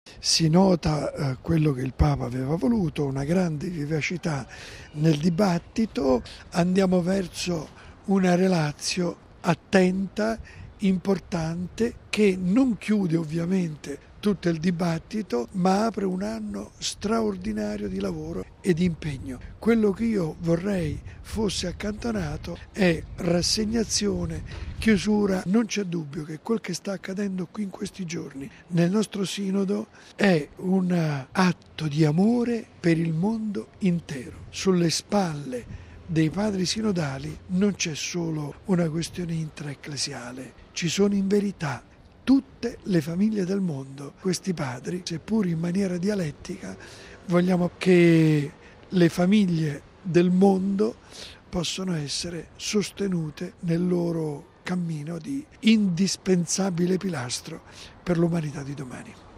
Questo il commento ai lavori del Sinodo espresso da mons. Vincenzo Paglia, presidente del Pontificio Consiglio della Famiglia